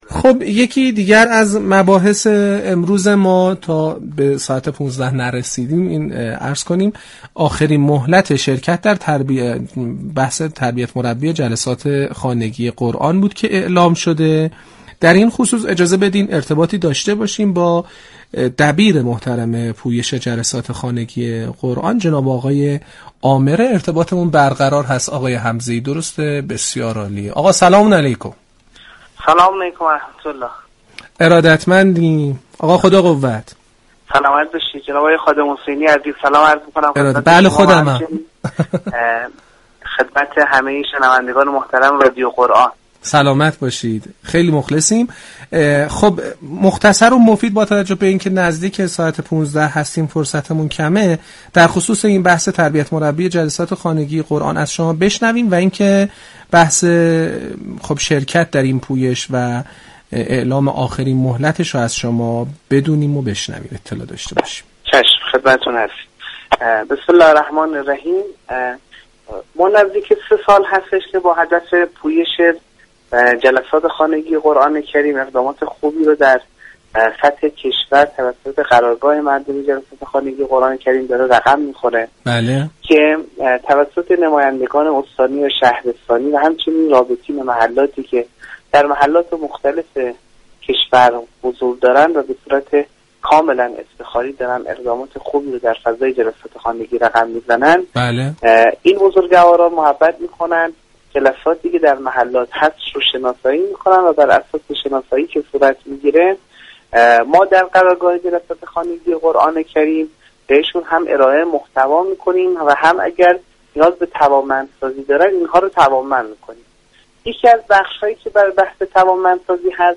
گفتنی است؛ برنامه عصرگاهی "والعصر" كه با رویكرد اطلاع رسانی یكشنبه تا چهارشنبه ی هر هفته بصورت زنده از رادیو قرآن پخش می شود.